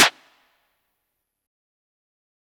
Waka SNARE ROLL PATTERN (79).wav